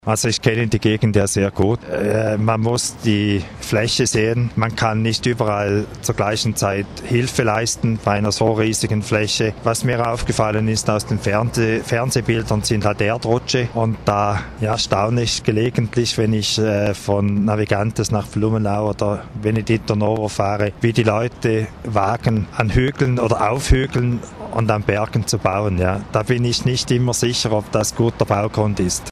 Depoimento do deputado federal suíço Thomas Müller (PDC), que tem casa em Navegante, sobre a enchente em Santa Catarina